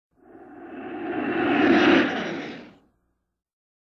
BSG FX - Viper - Pass by fast 02
BSG_FX_-_Viper_-_Pass_by_fast_02.wav